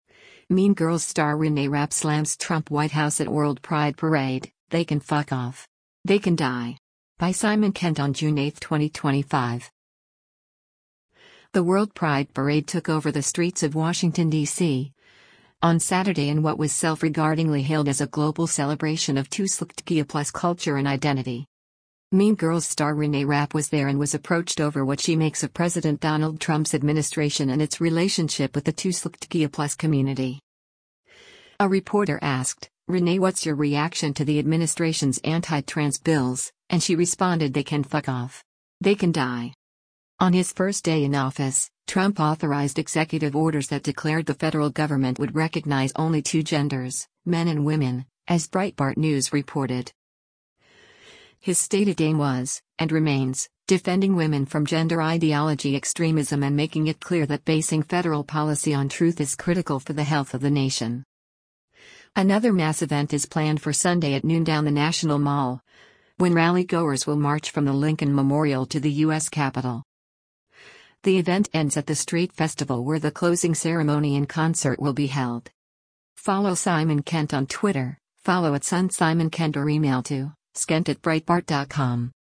‘Mean Girls’ Star Reneé Rapp Slams Trump White House at World Pride Parade: ‘They Can F**k Off. They Can Die’
A reporter asked, “Renee what’s your reaction to the administration’s anti-trans bills,” and she responded “They can fuck off.. they can die.”